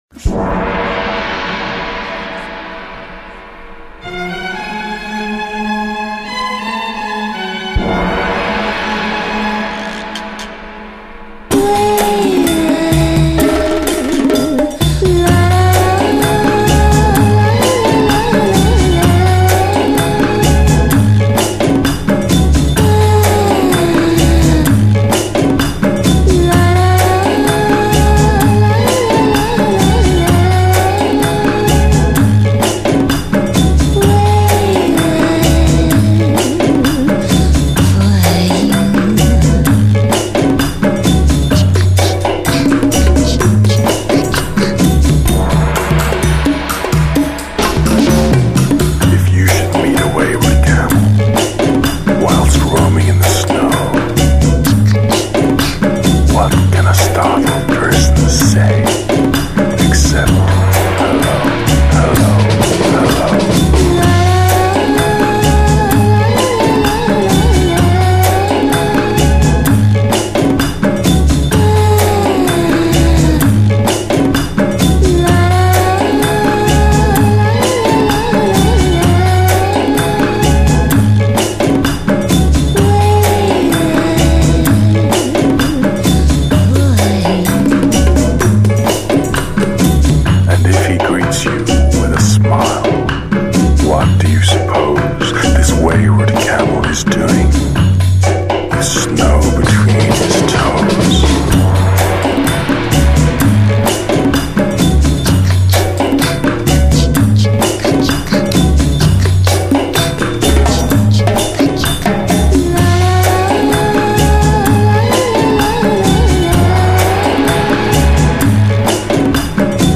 甜美中氤蕴迷幻，舒适解压的聆听旅程。
乐风涵盖 Bossa Nova，Downtempo，Nu Jazz 与 Lounge